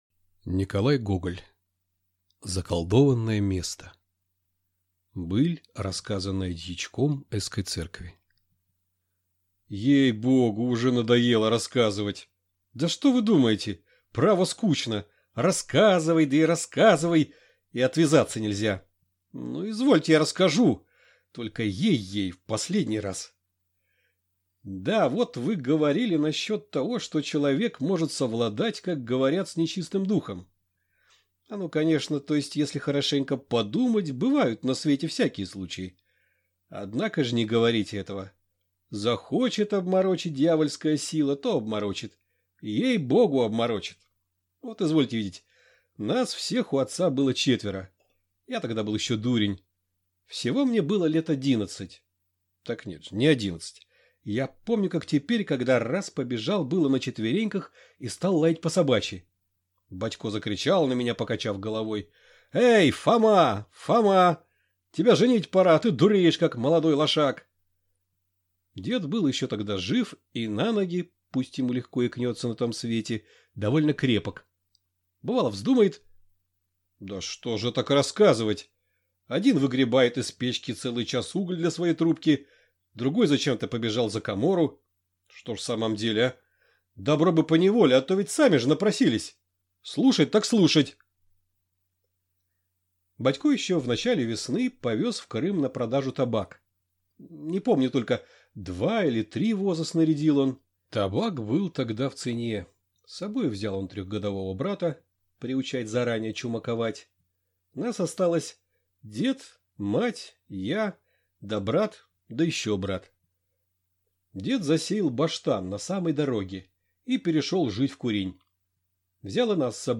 Аудиокнига Заколдованное место. Быль, рассказанная дьячком ***ской церкви | Библиотека аудиокниг